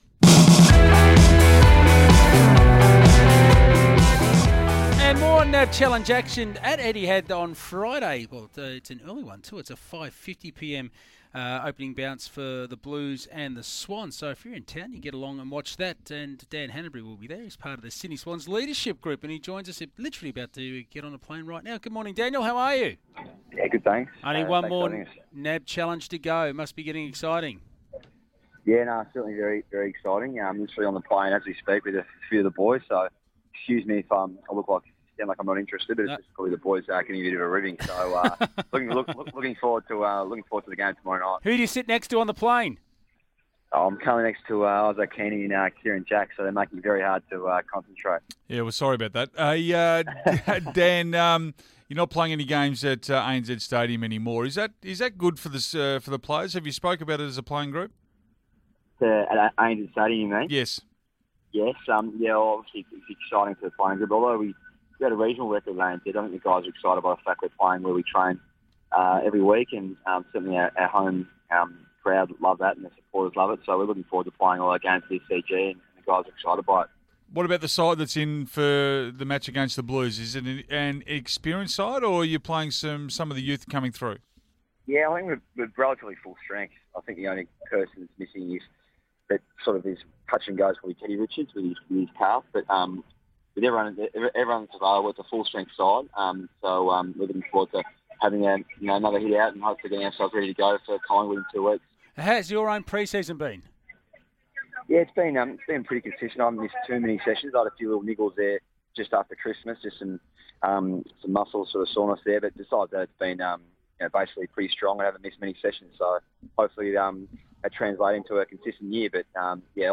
Sydney Swans midfielder Dan Hannebery speaks to the boys on SEN Breakfast ahead of Friday's final NAB Challenge clash against Carlton.